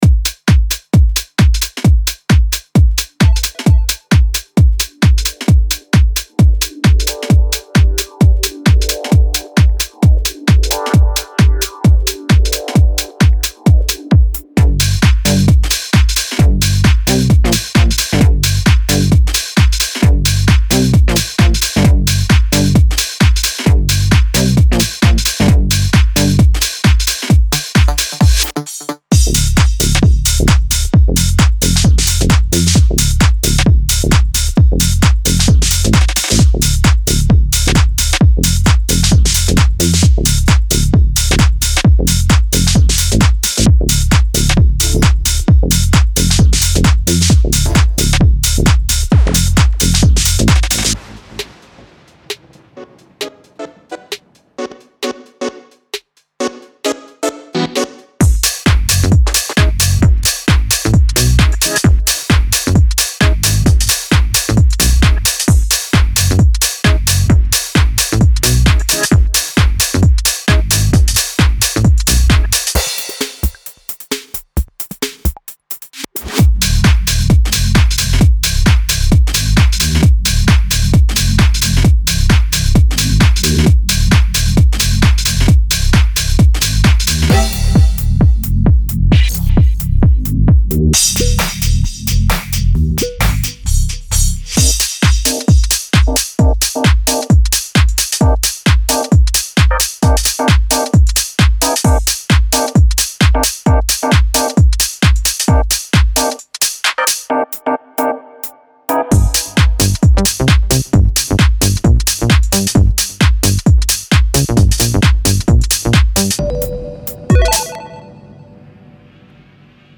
此音色包中的音色非常适合现代极简浩室、车库、科技浩室等多种音乐风格。
所有循环的播放速度均在 130bpm 至 135bpm 之间。